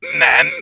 soldier_medic02.mp3